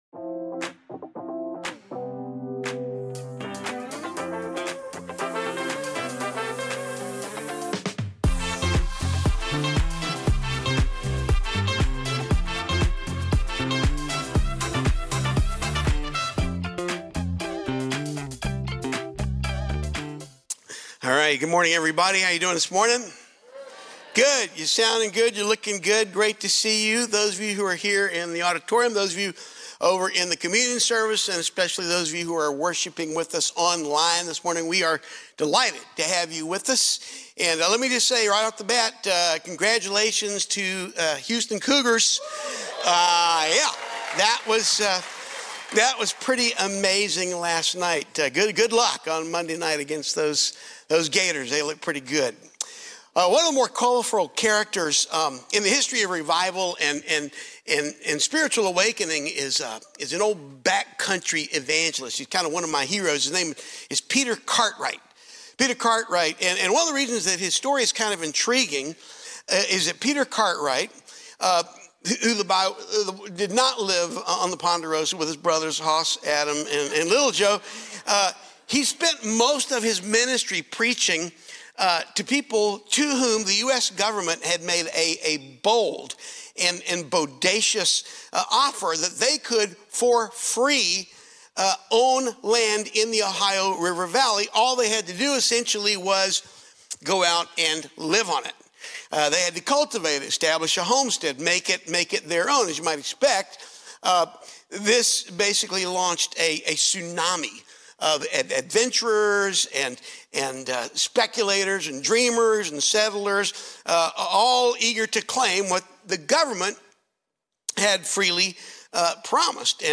Weekly biblically-based sermons from Faithbridge church in Spring, Texas.